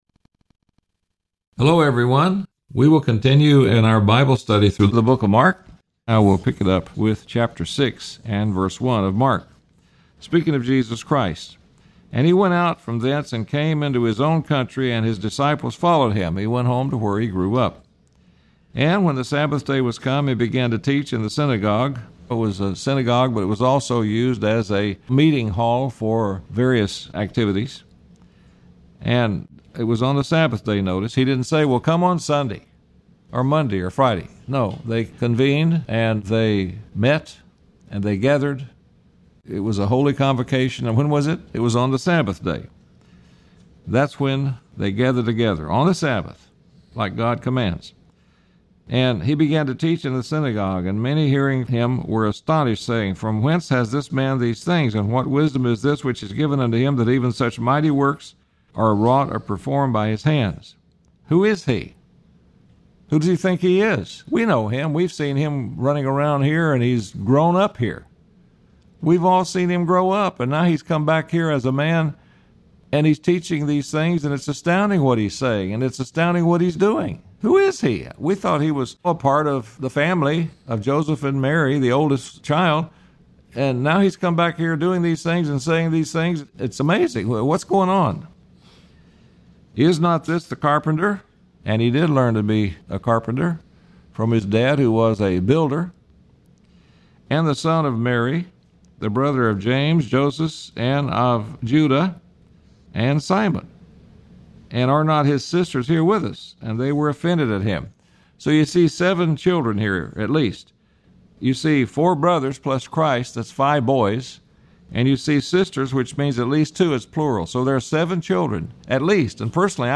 A verse by verse study of the book of Mark